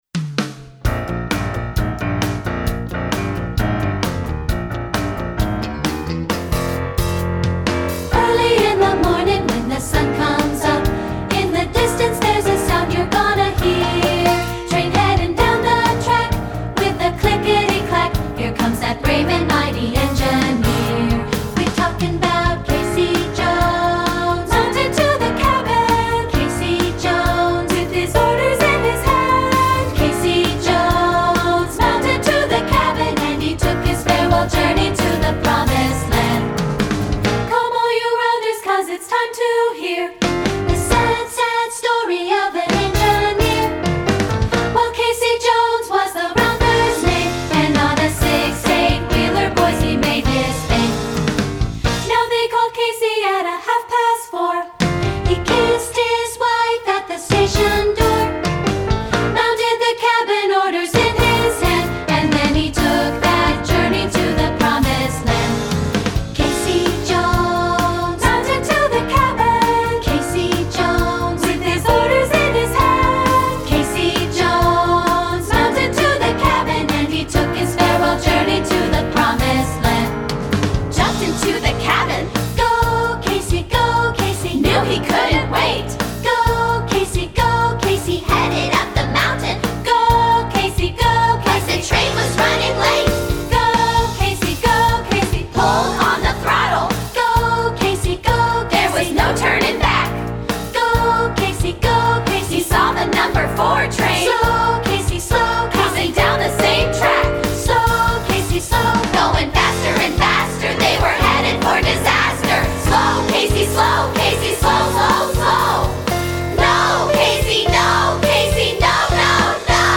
Choral Folk
American Folk Song